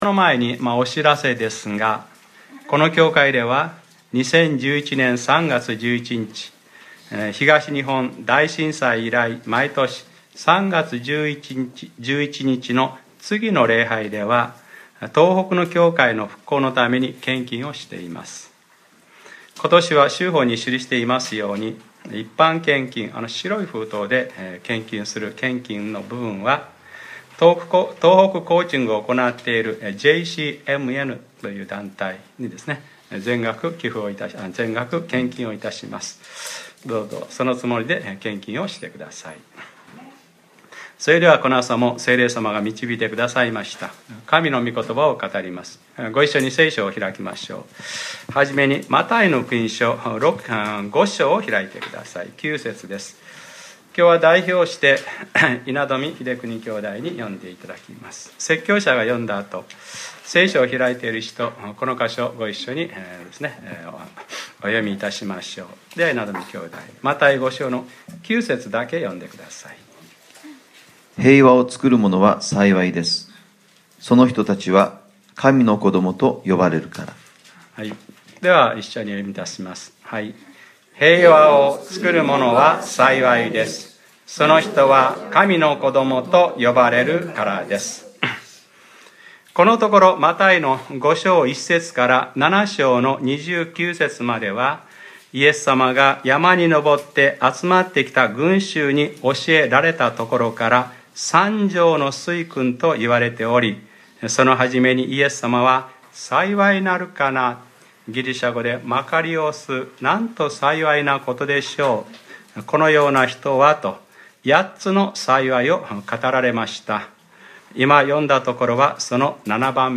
2015年03月15日）礼拝説教 『 平和（シャローム）』